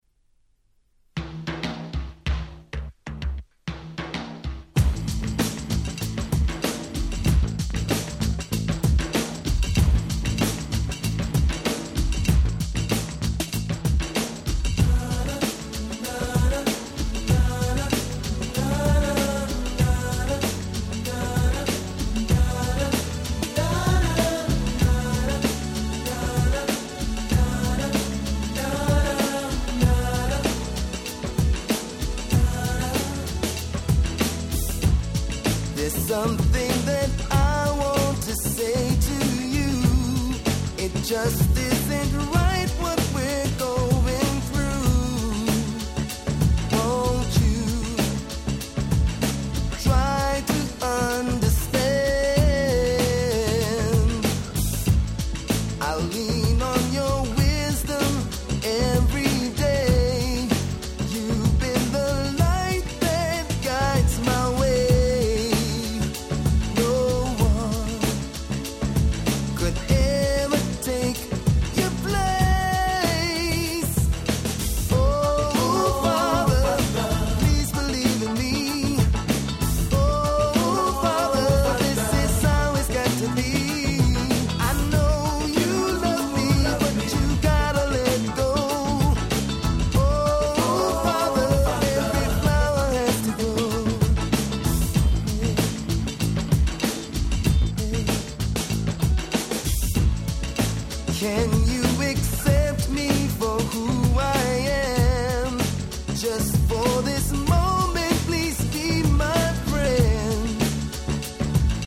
人気曲A-3(アナログはこの盤オンリー！)、GroovyなB-1を始め陽気な感じの良曲多数！